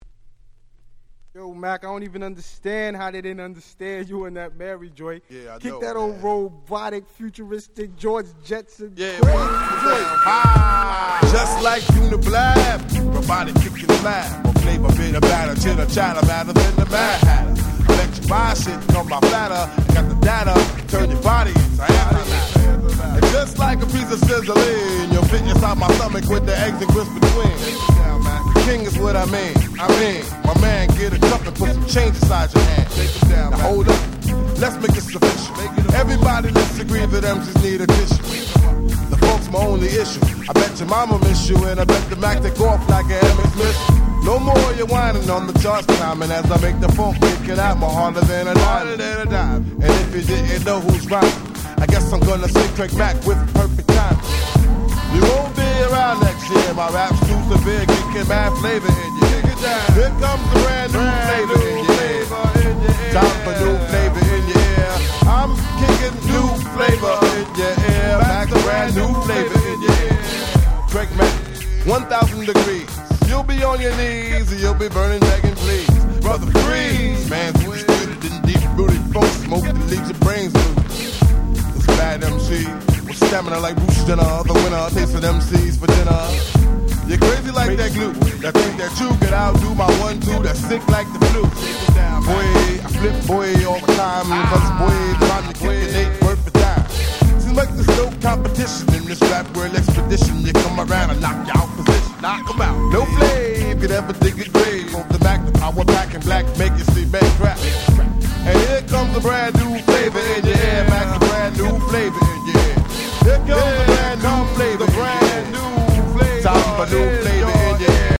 94' Super Hit Hip Hop !!